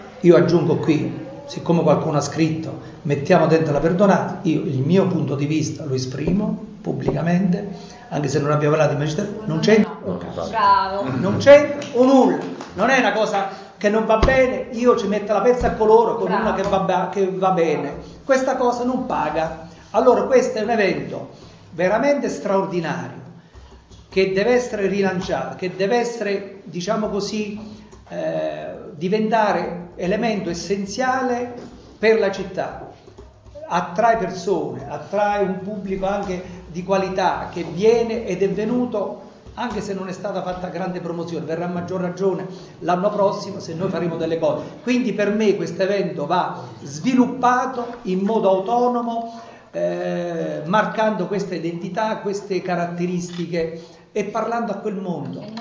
A proposito di un articolo di ieri pubblicato su questo giornale, dove vi abbiamo raccontato della conferenza stampa dell'assessore comunale al Turismo Lelio De Santis, quest'ultimo ha inviato alla redazione di NewsTown una precisazione, nella quale smentisce di aver detto che Jazz italiano per L'Aquila "non c'entra un c..." con la Perdonanza (in riferimento all'inserimento della maratona jazz nel cartellone dell'evento celestiniano).